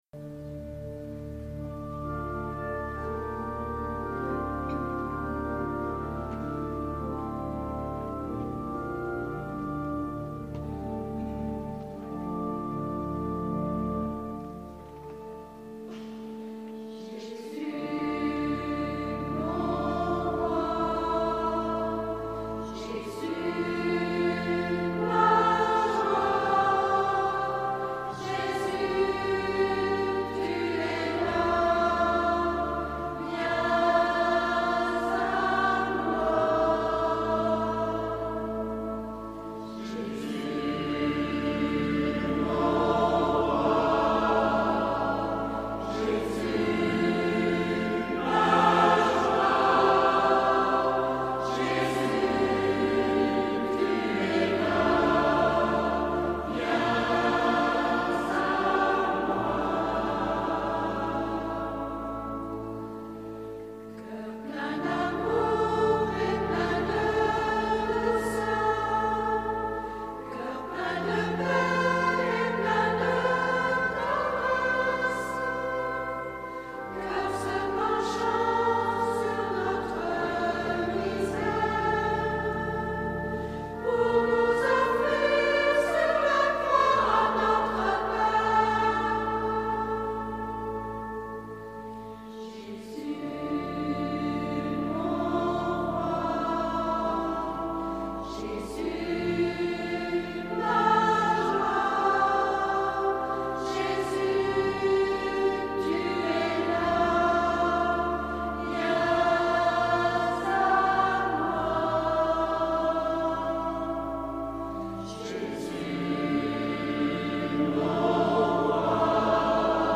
Chorales paroissiales